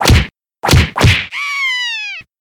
TeeHammer.mp3